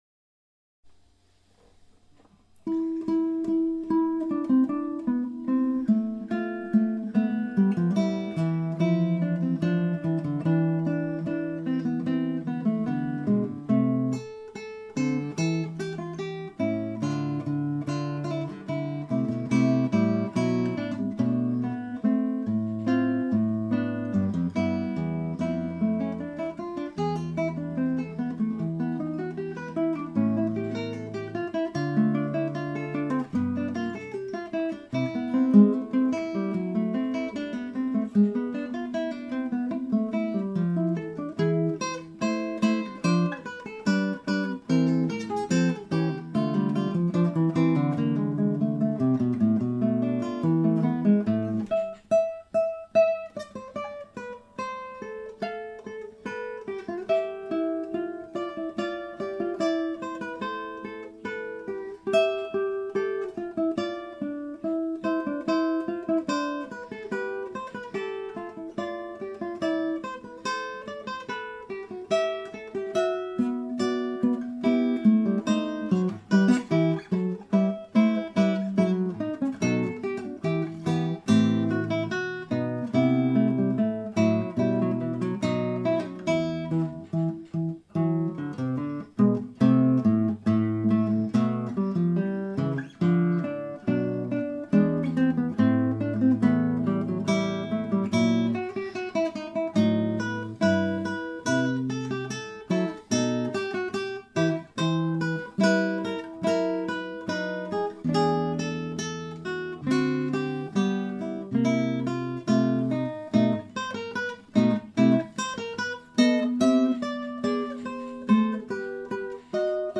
recorded with a pair of Behringer C2s.
If you can setup multiple types of SDC on classical guitar one of the things players are always after it low pickup of squeaks.